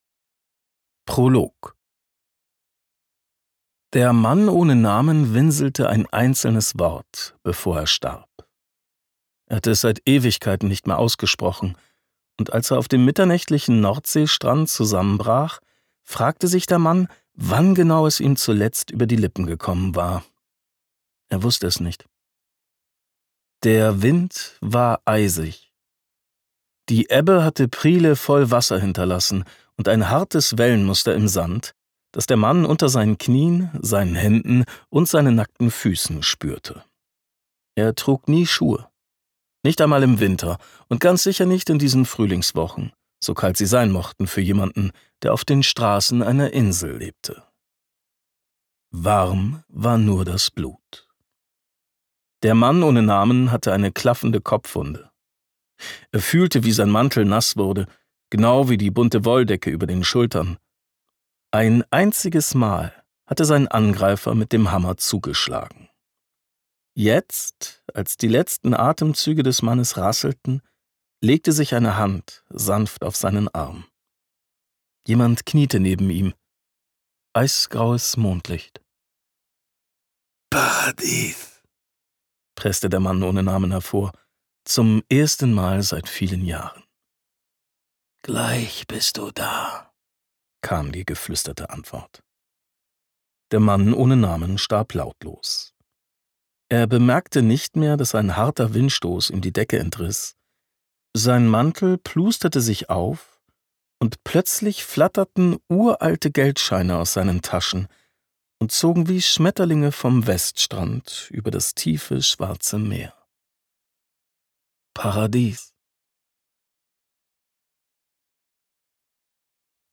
Gekürzt Autorisierte, d.h. von Autor:innen und / oder Verlagen freigegebene, bearbeitete Fassung.
Hörbücher mit verwandten Themen